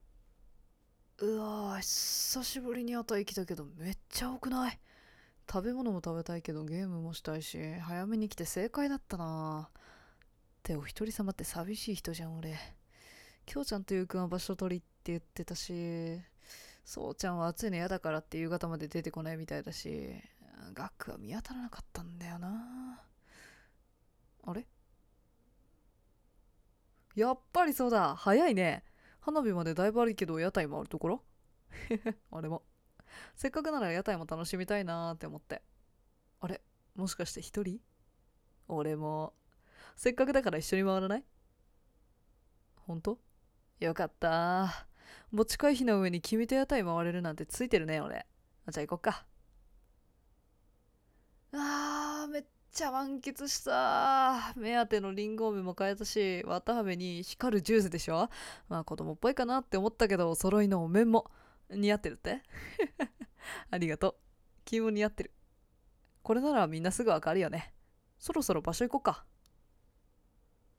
ソロ声劇①